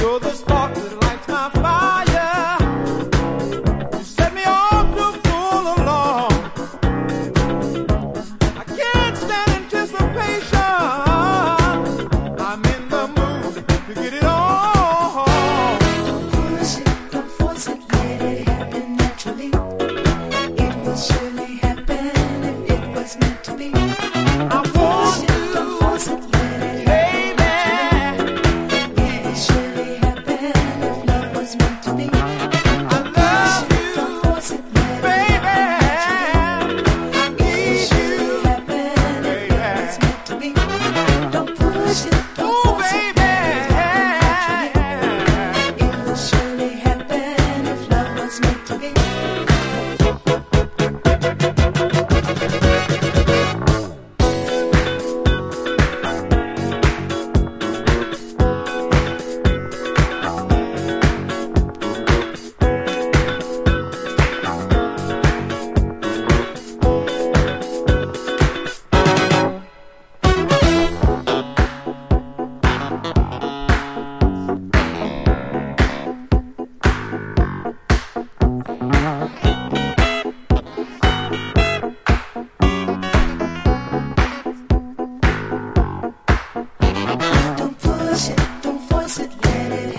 ブギーな感じのベースラインが最高な長尺曲。
LOFT / GARAGE